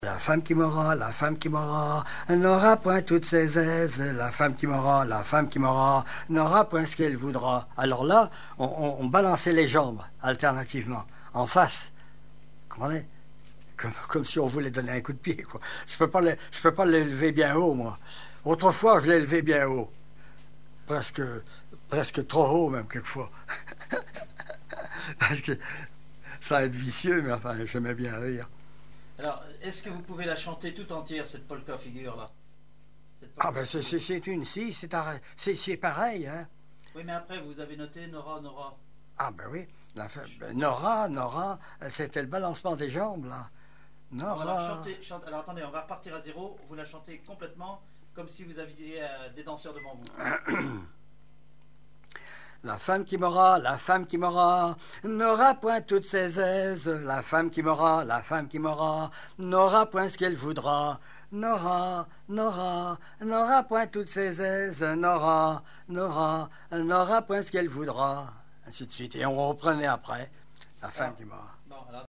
Chants brefs - A danser
danse : polka
Pièce musicale inédite